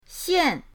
xian4.mp3